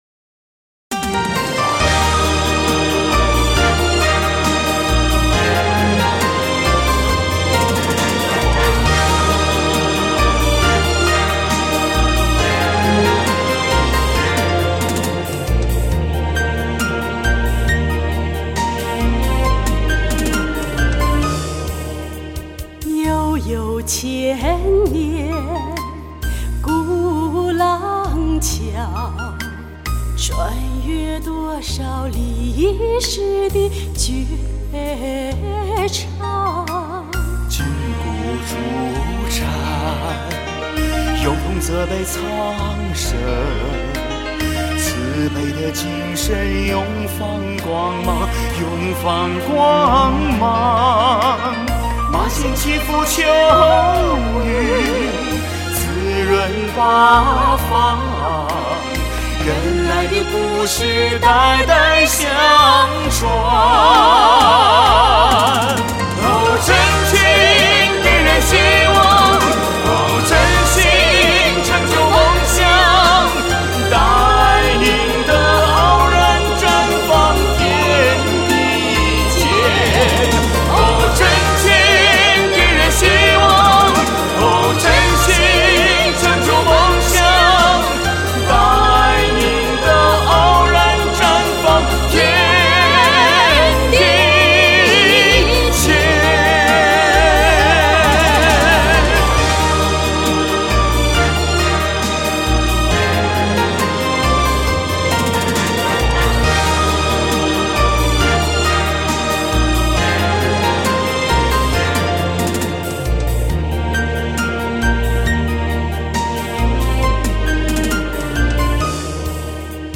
歌曲曲调采用群众性歌曲的创作手法，旋律朗朗上口，十分亲切。